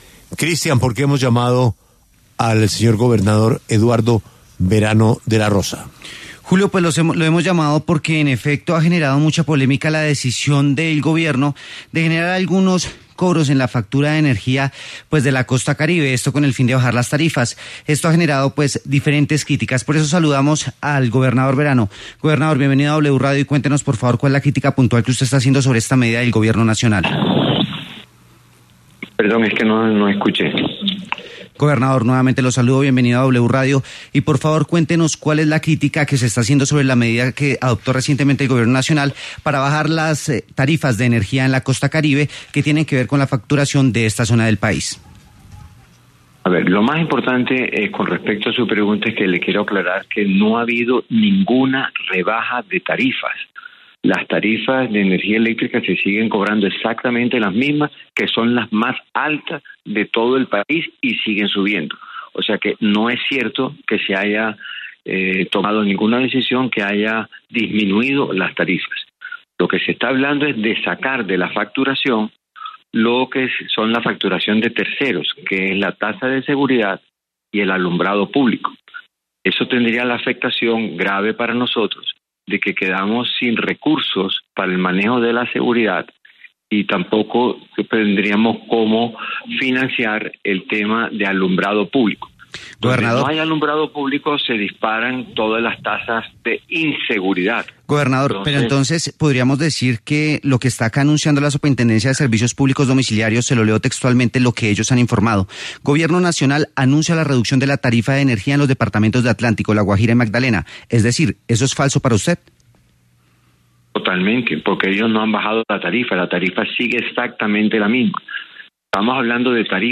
Eduardo Verano, gobernador del Atlántico, conversó en La W sobre sus críticas a las medidas para reducir las tarifas de energía en los departamentos de la costa Caribe.